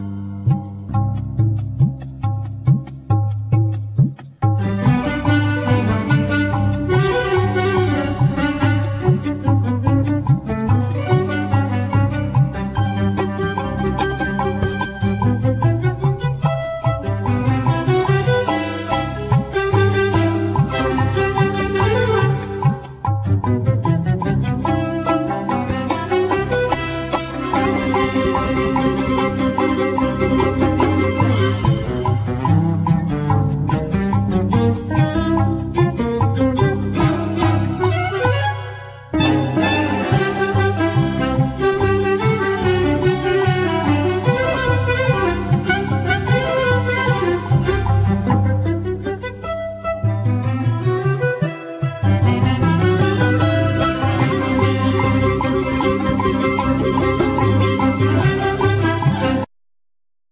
Clarinet
Keyboards,Short Waves
Cello
Percussions
Chapman Stick
Violin
Viola
French Horn
Guitar